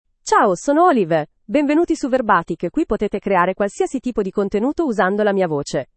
OliveFemale Italian AI voice
Olive is a female AI voice for Italian (Italy).
Voice sample
Listen to Olive's female Italian voice.
Olive delivers clear pronunciation with authentic Italy Italian intonation, making your content sound professionally produced.